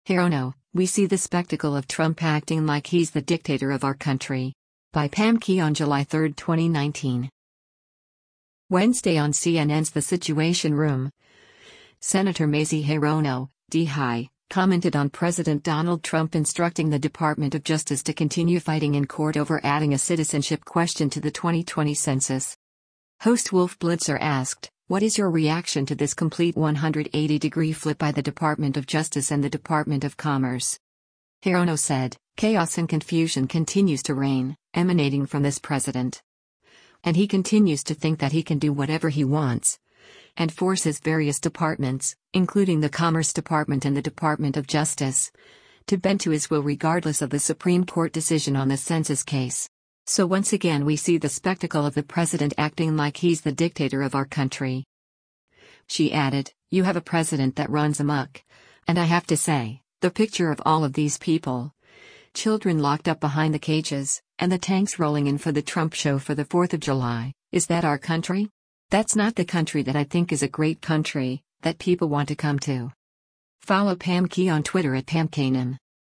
Wednesday on CNN’s “The Situation Room,” Sen. Mazie Hirono (D-HI) commented on President Donald Trump instructing the Department of Justice to continue fighting in court over adding a citizenship question to the 2020 Census.
Host Wolf Blitzer asked, “What is your reaction to this complete 180-degree flip by the Department of Justice and the Department of Commerce?”